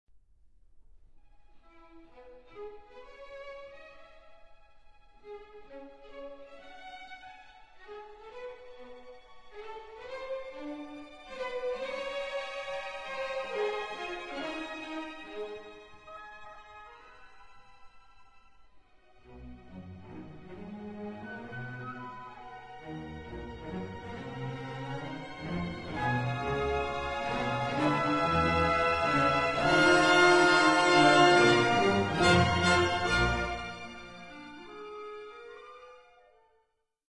Bewegt, doch nicht schnell 12:41